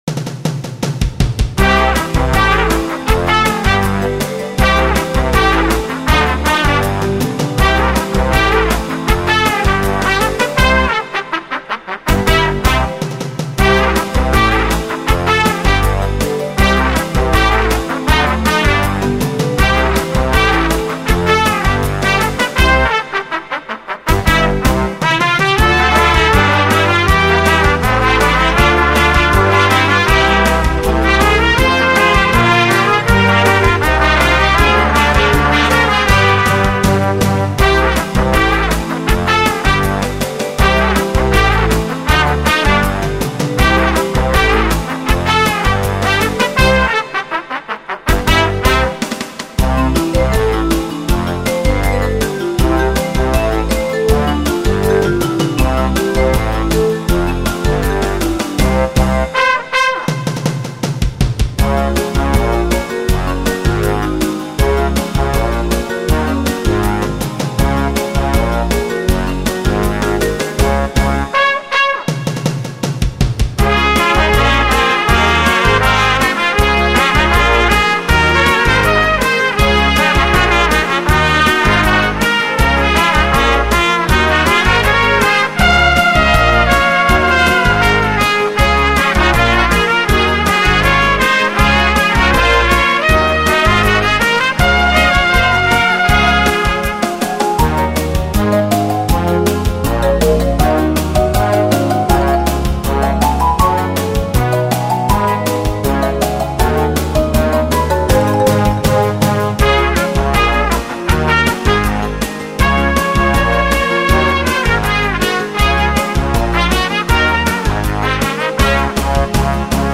2-3köpfige professionelle Tanz- und Showband
• Allround Partyband
• Coverband